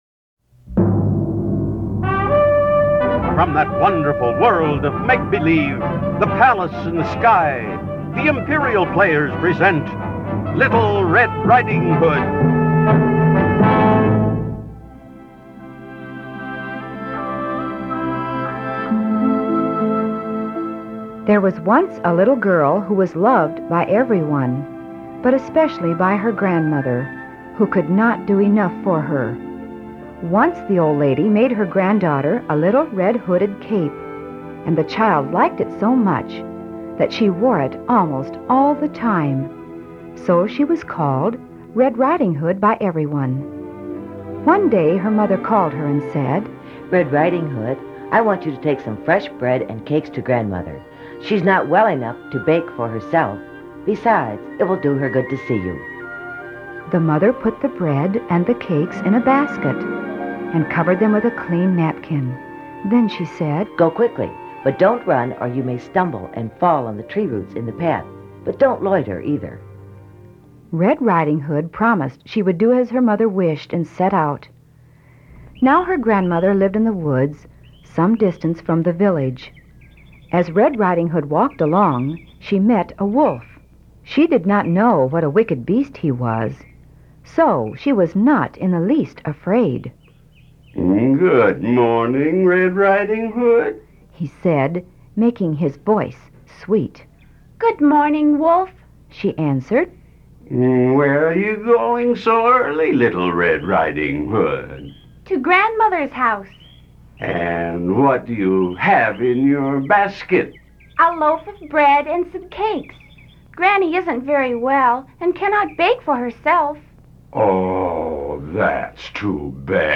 Narration by expert storytellers, music, exciting sound effects, and dramatic dialogue will inspire children to read.